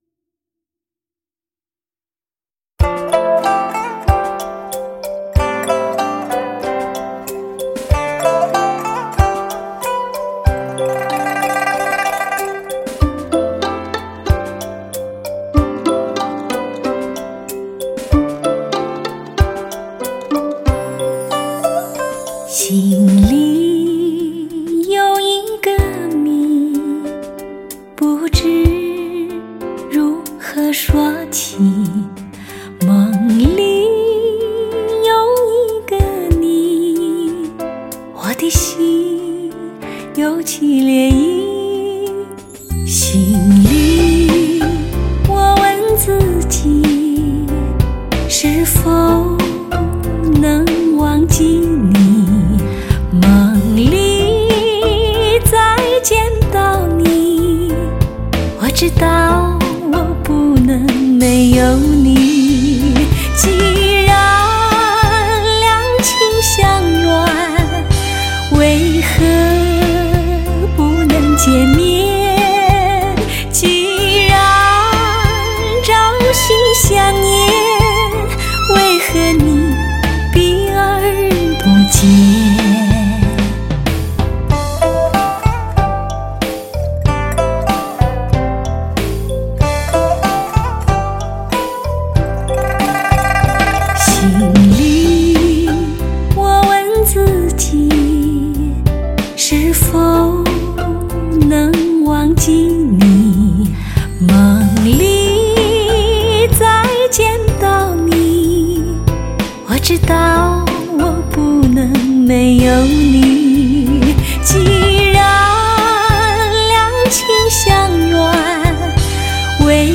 出水芙蓉的声线  聆听夏日的清风……